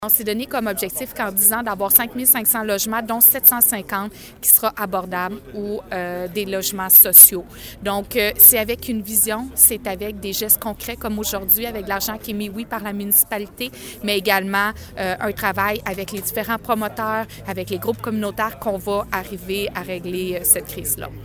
La mairesse de Granby, Julie Bourdon, estime que c’est un bon pas dans le dossier de la crise du logement et note que ce n’est pas le dernier :